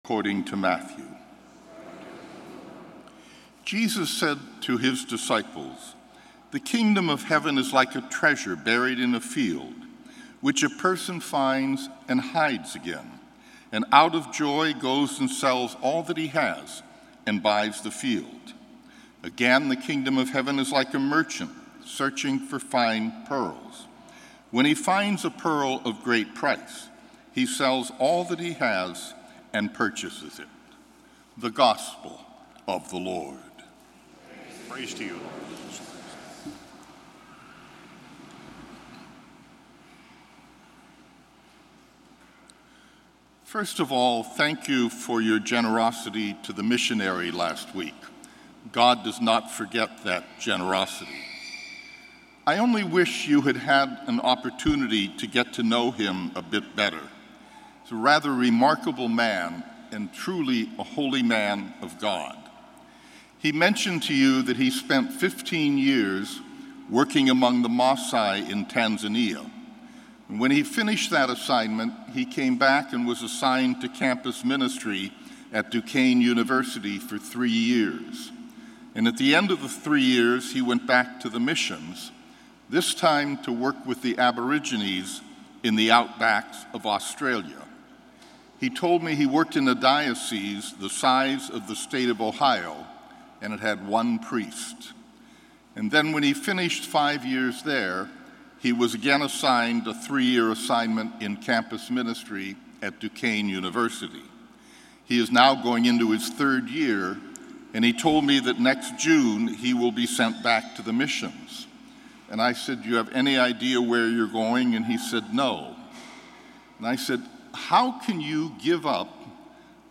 Gospel & Homily July 30, 2017